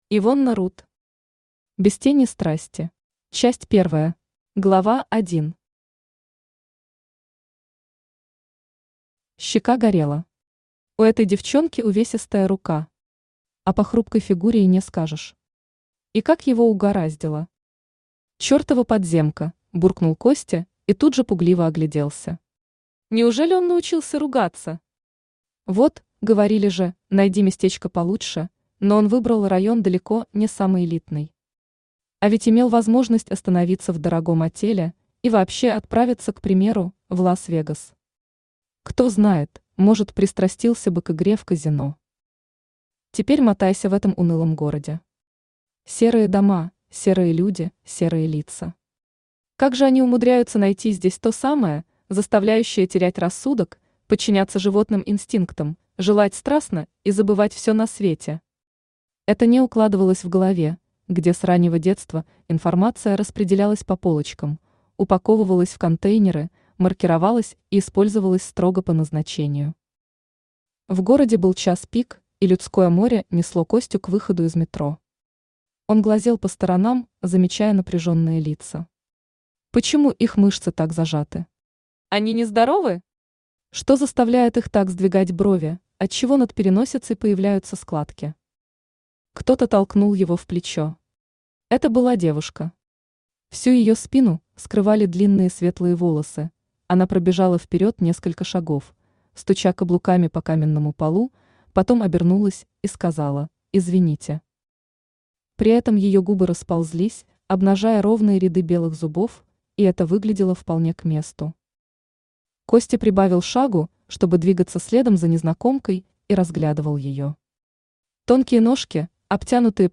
Aудиокнига Без тени страсти Автор Ивонна Рут Читает аудиокнигу Авточтец ЛитРес.